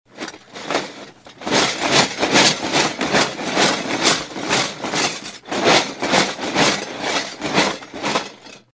Звуки картона
Звук тряски коробки с металлическими предметами или инструментами